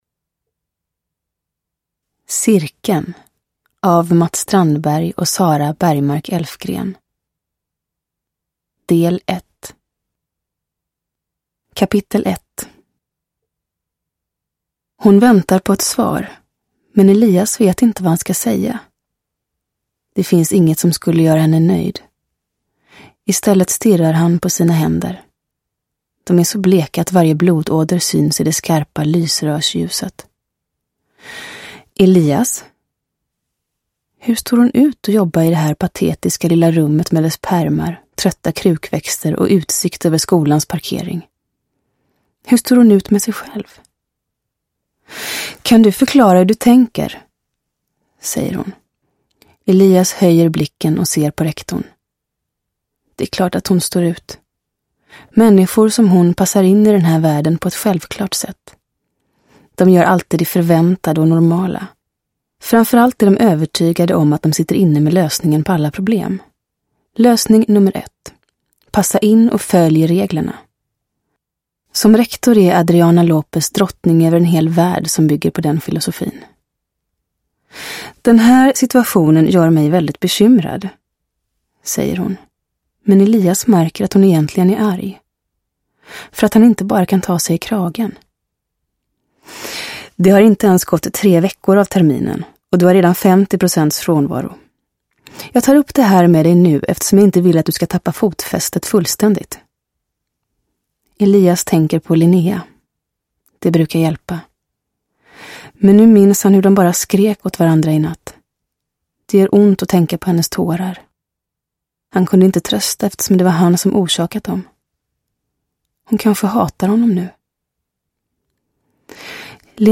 Cirkeln – Ljudbok – Laddas ner